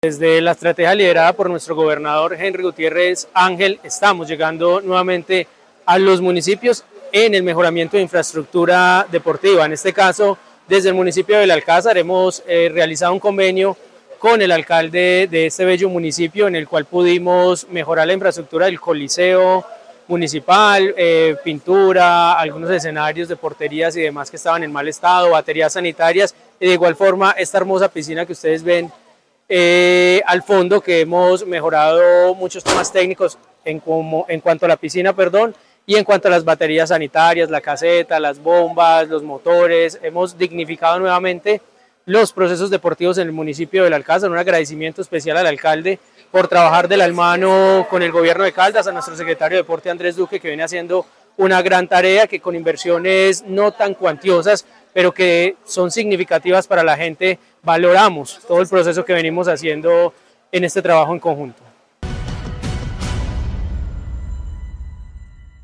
Ronald Fabián Bonilla Ricardo, secretario Privado